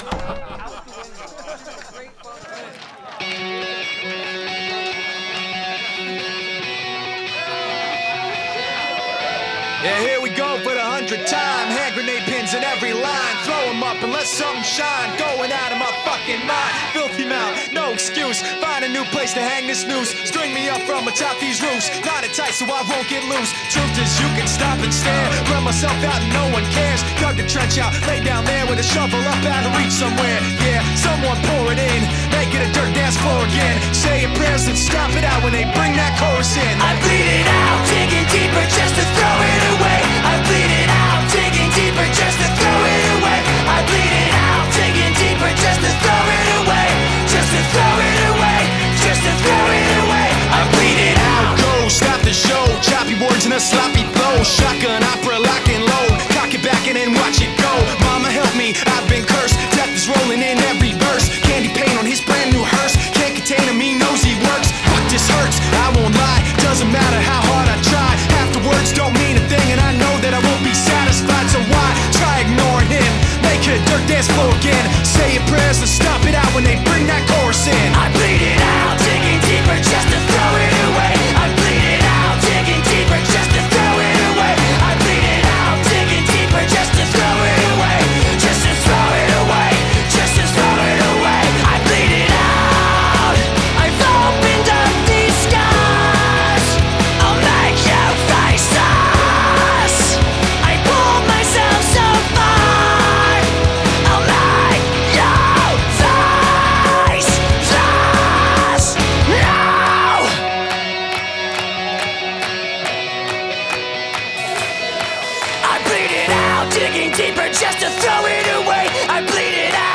• Жанр: Alternative, Rock